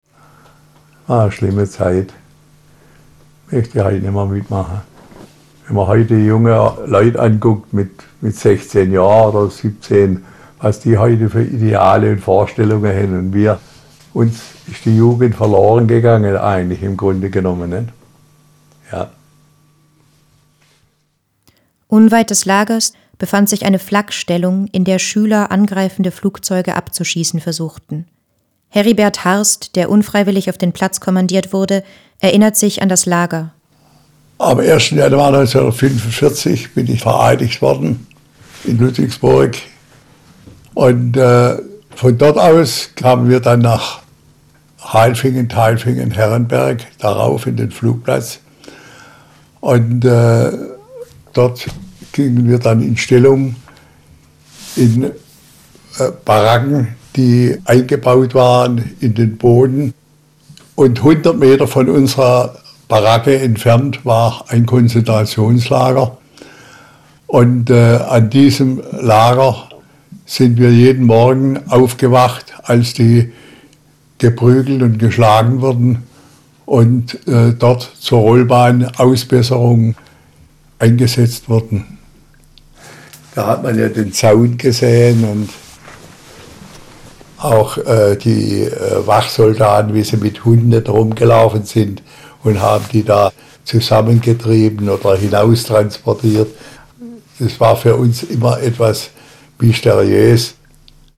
Audioguide Titel 4
hohe Audioqualität